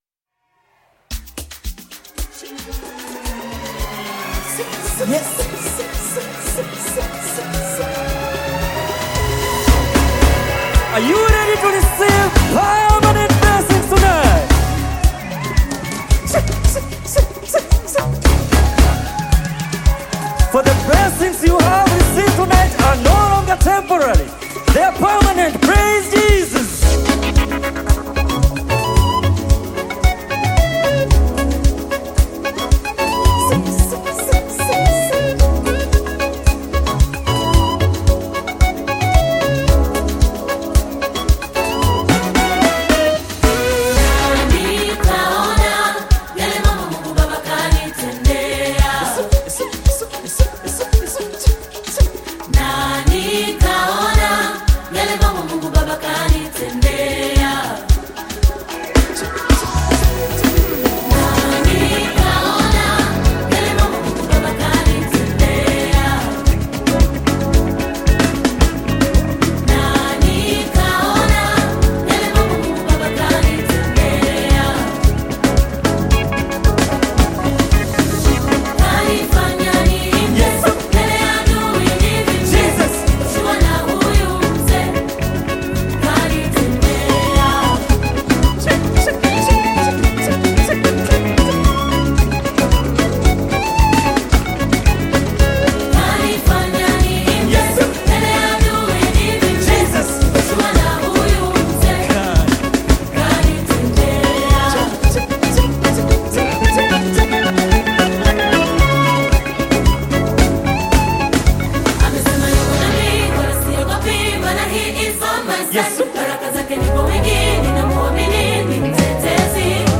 Gospel music track
Tanzanian gospel music group